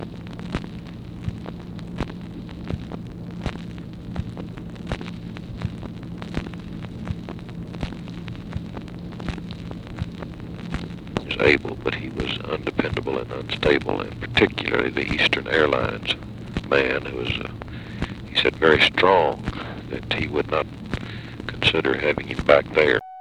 Conversation with WALTER JENKINS, April 22, 1964
Secret White House Tapes